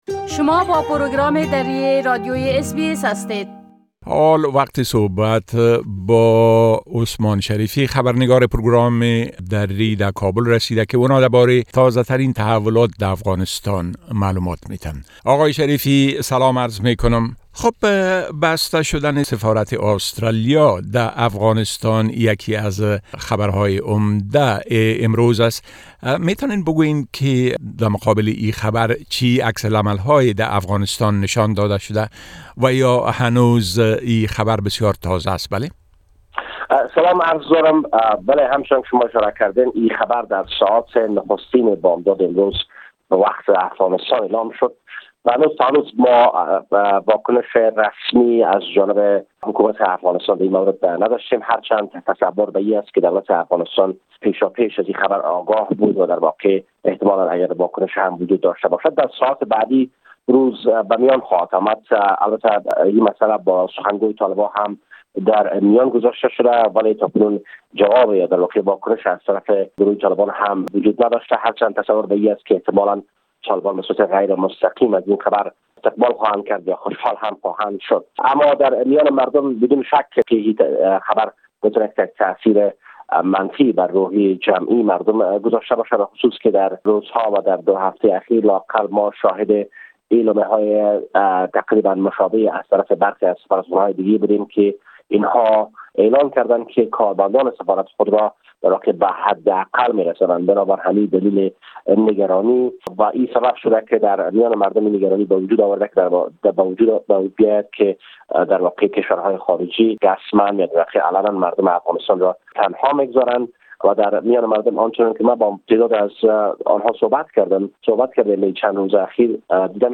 گزارش كامل خبرنگار ما در كابل، به شمول اوضاع امنيتى و تحولات مهم ديگر در افغانستان را در اينجا شنيده ميتوانيد.